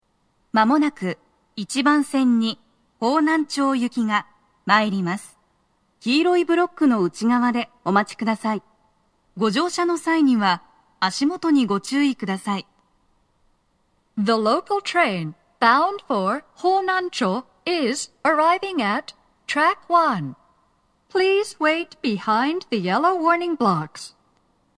スピーカー種類 TOA天井型()
鳴動は、やや遅めです。
１番線 方南町方面 接近放送 【女声